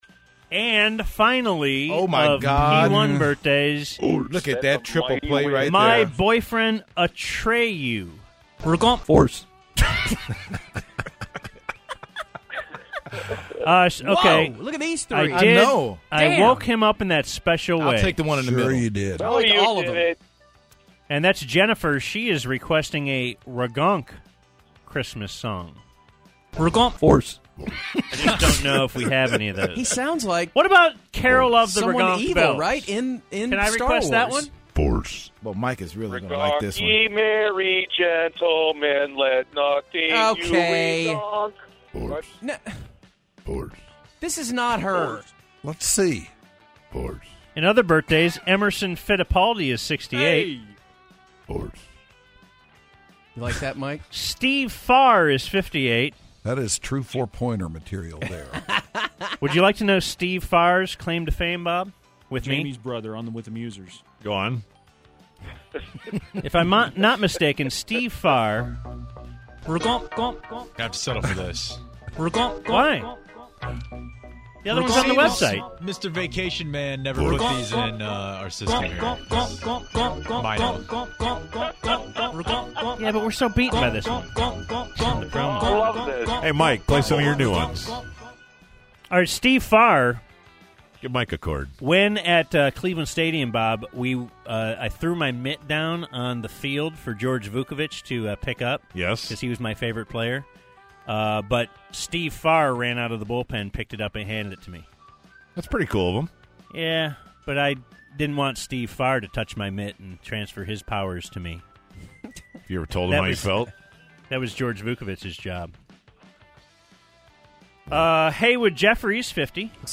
Ragonk Talk breaks out during WTDS and we hear some new Ragonk songs